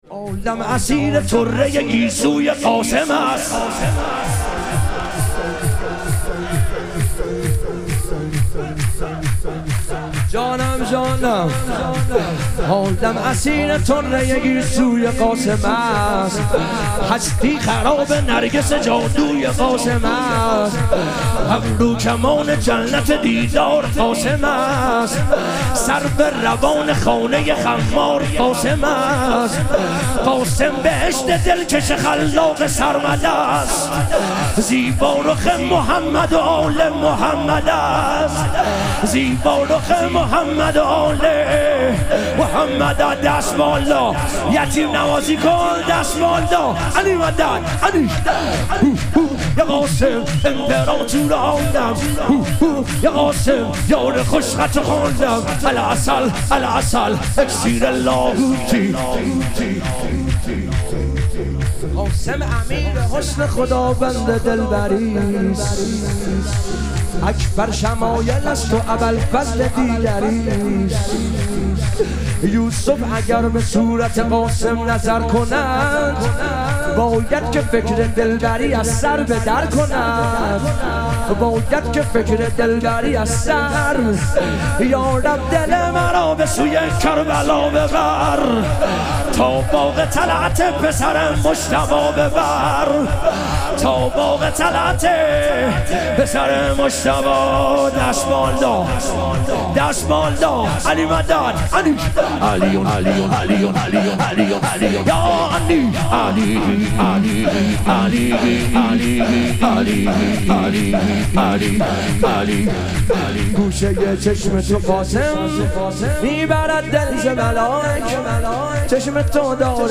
ظهوروجود مقدس امام هادی علیه السلام - شور